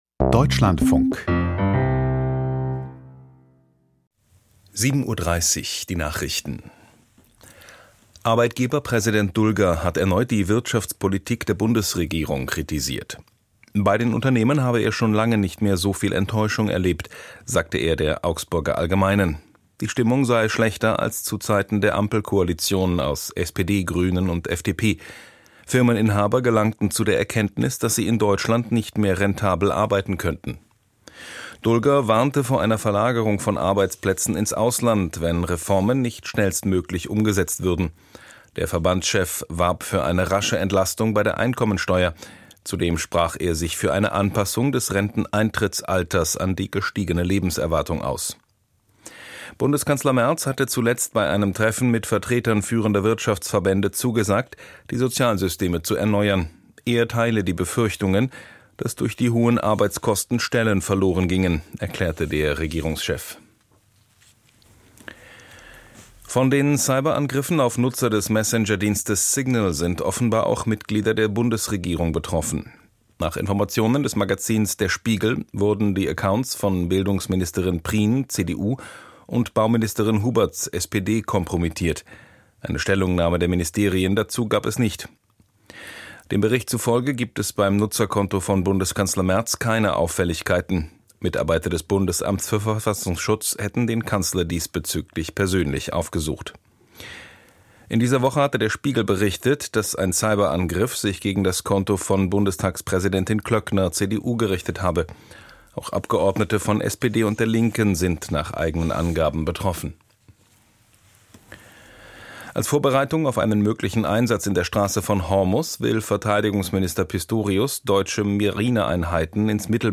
Die Nachrichten vom 25.04.2026, 07:30 Uhr
Aus der Deutschlandfunk-Nachrichtenredaktion.